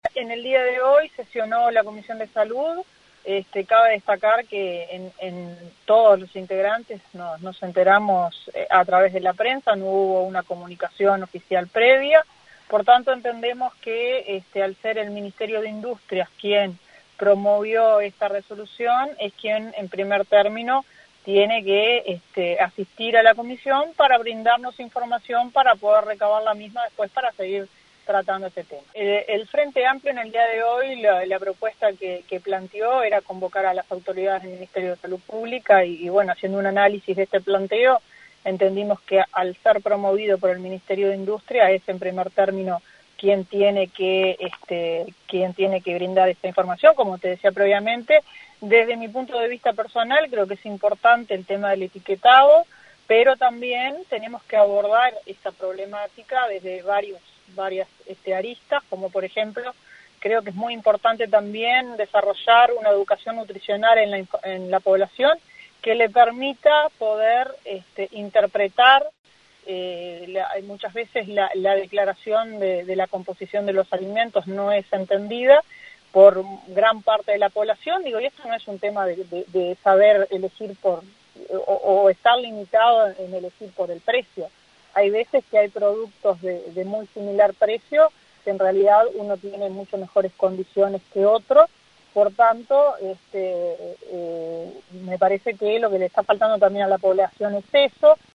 La diputado del Partido Colorado, Nibia Reisch, en diálogo con 970 Noticias dijo que es importante el tema del etiquetado y que hay que abordar esta problemática desde varias aristas.